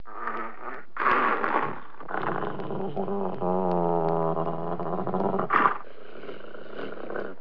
دانلود صدای حیوانات جنگلی 48 از ساعد نیوز با لینک مستقیم و کیفیت بالا
جلوه های صوتی